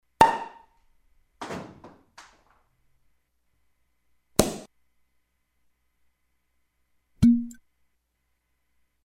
SFX – CORK POPS
SFX-CORK-POPS.mp3